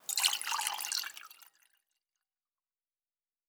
Fantasy Interface Sounds
Food Drink 01.wav